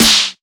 Chart Snare 03.wav